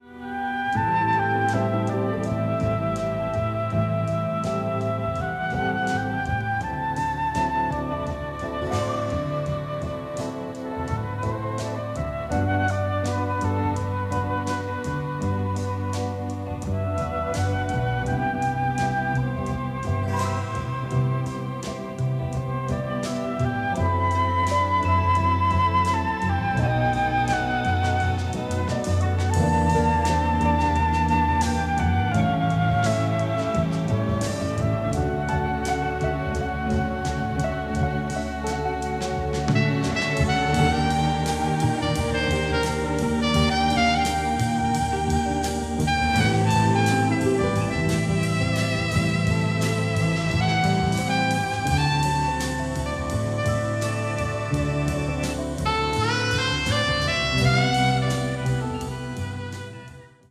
a wonderful romantic horror score
in beautiful pristine stereo